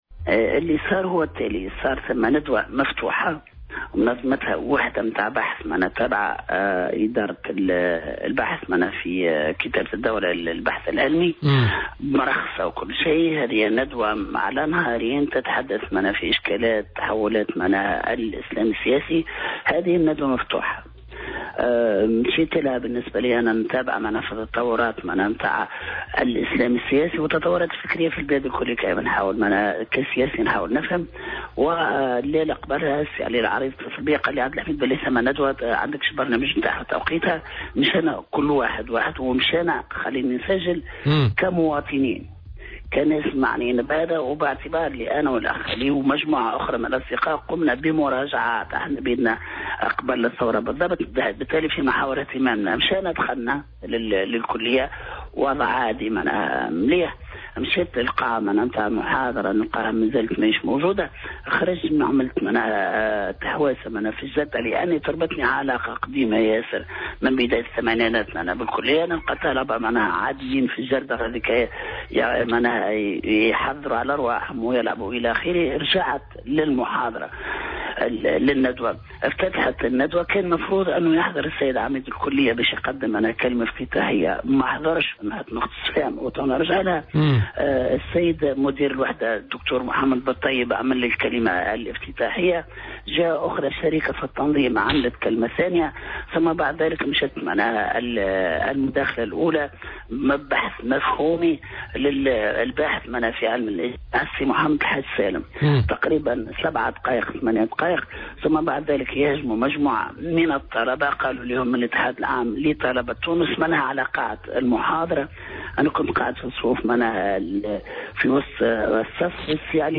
وأبرز الجلاصي، لدى تدخله في برنامج "بوليتكا" اليوم الاثنين، أن مجموعة من الطلبة ينتمون للاتحاد العام لطلبة تونس Uget هجموا فجأة، في حالة من الهستيريا والهيجان، على قاعة المحاضرة بكلية الآداب، ورفعوا شعارات مناوئة لحركة النهضة التي حملوها مسؤولية "أحداث الرش" في سليانة، خاصة وأن القيادي الآخر علي العريّض كان حاضرا في الندوة واضطر للانسحاب.